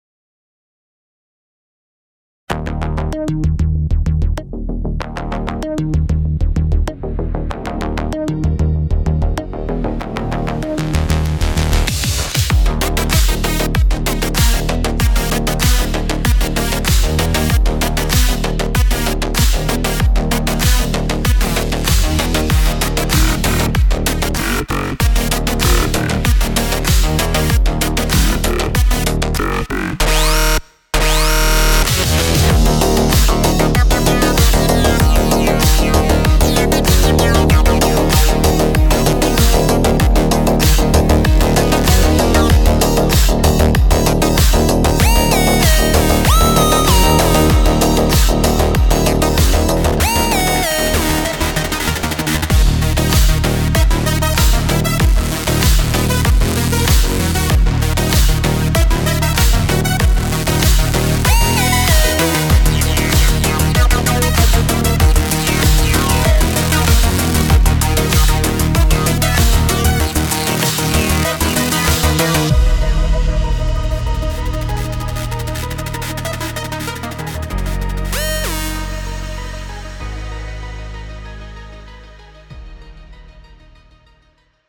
From metallic portal pulses to glitchy spatial gates and futuristic transitions, Lost Signals delivers a full arsenal of gating effects that evoke ancient machinery, high-tech doors, and otherworldly dimensional shifts.
• 100 dedicated gate presets: sci-fi inspired gates, stutters, rhythmic pulses, spatial transitions and cinematic gating effects.
• Cinematic and futuristic tone: each preset sculpted for clarity, depth, and an otherworldly vibe — perfect for sci-fi, cyberpunk, cinematic and hybrid sound design.
• * The video and audio demos contain presets played from Lost Signals sound bank, every single sound is created from scratch with Spire.